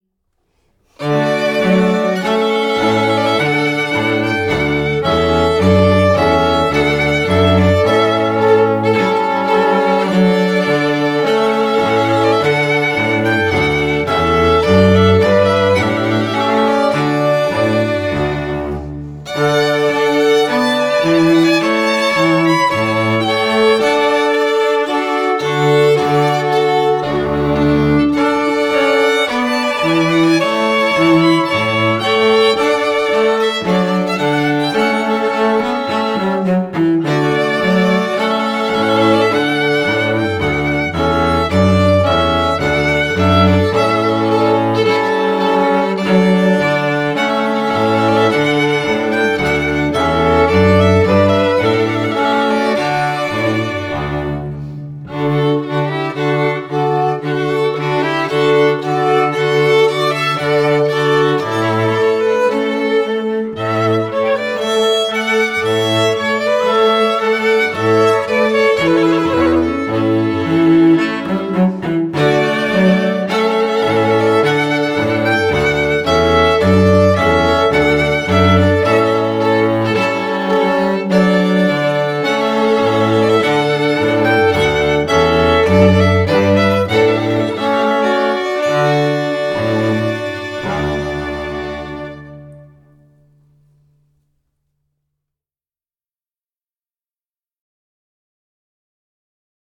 Enjoy a sampling of popular traditional wedding songs Canon in D by Pachelbel La Rejouissance by Handel Trumpet Voluntary by Clark Jesu, Joy of Man’s Desiring by J.S. Bach Website Designed by Richard Creative